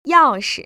[yào‧shi] 야오스